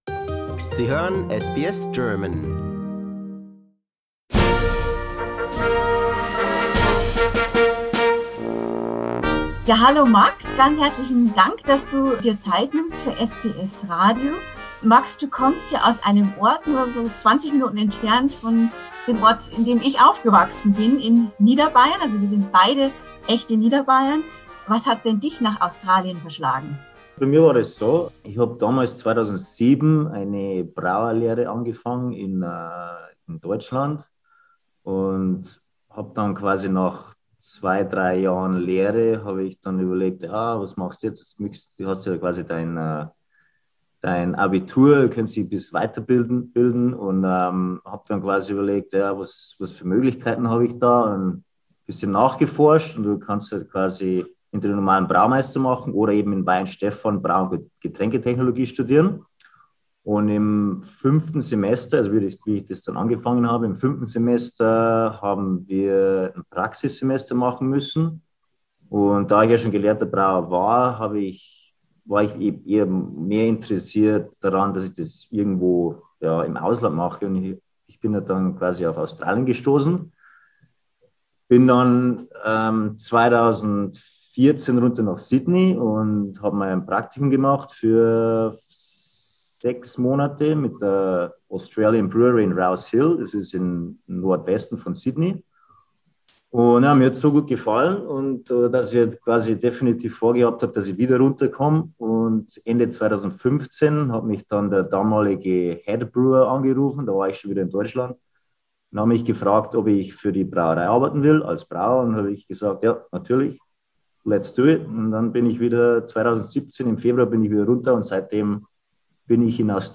Im SBS-Interview erzählt er, wie es ihn nach Australien verschlug und warum australische Biere ganz anders (und oft genau so gut!) schmecken wie Biere Made in Germany.